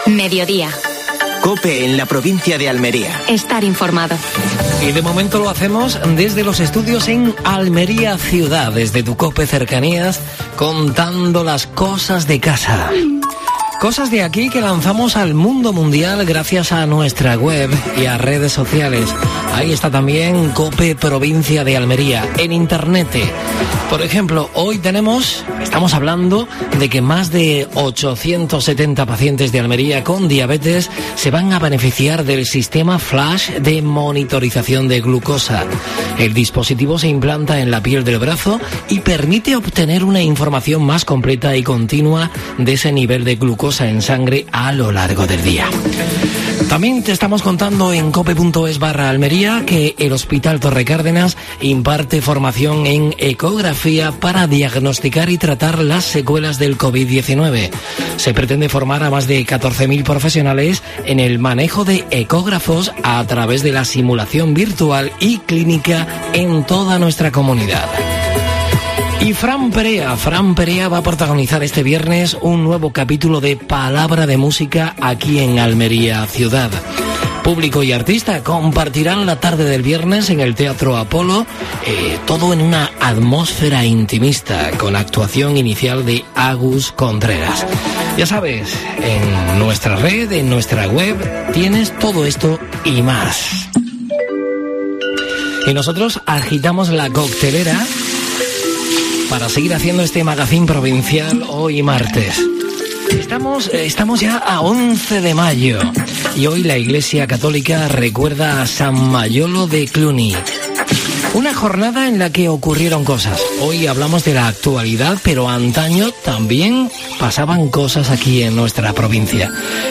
El tiempo, con José Antonio Maldonado. Última hora deportiva.